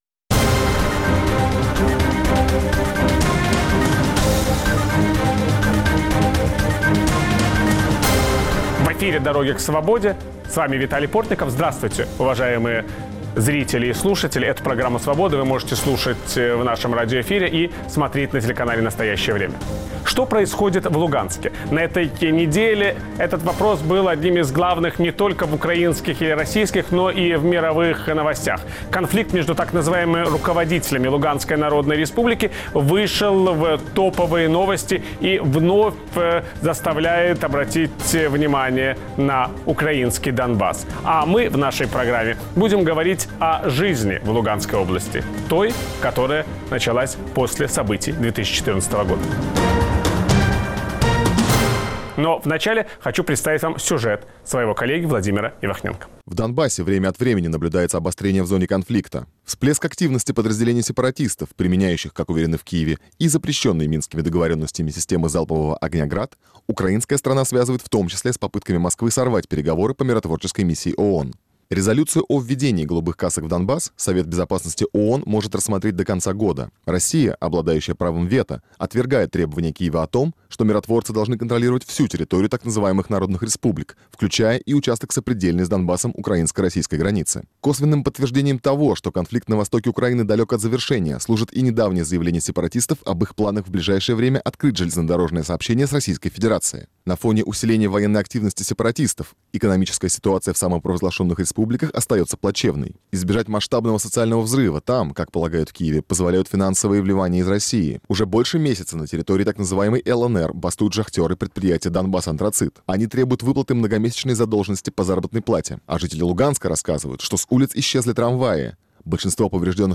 Что происходит в Луганской области, разделённой сегодня линией разграничения? Виталий Портников беседует с бывшей главой Луганской областной горадминистрации Ириной Веригиной